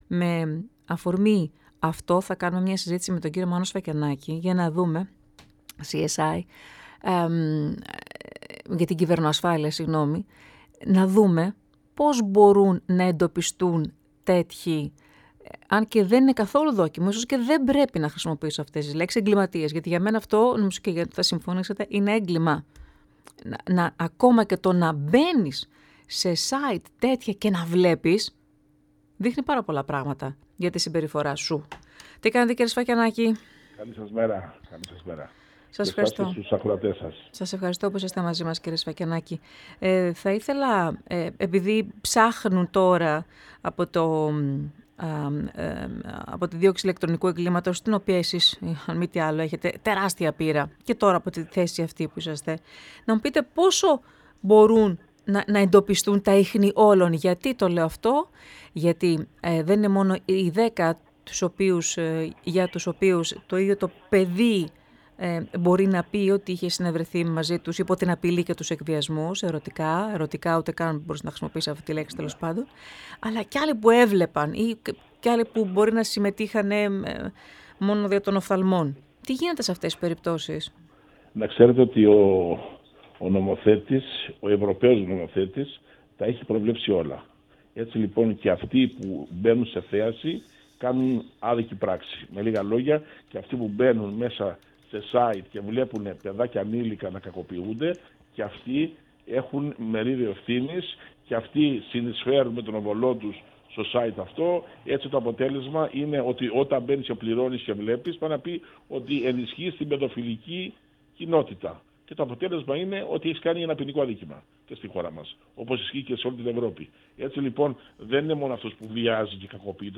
Ο πρόεδρος του CSI Institute, Μανώλης Σφακιανάκης μίλησε στο Πρώτο Πρόγραμμα 91,6 και 105,8 και αναφέρθηκε στο προφίλ των παιδόφιλων. Όπως δήλωσε, οι παιδόφιλοι δεν έχουν χαρακτηριστικό γνώρισμα… είναι άνθρωποι της διπλανής πόρτας, συνήθως είναι συγγενικά πρόσωπα γιατί αυτά εμπιστεύεται το οικογενειακό περιβάλλον.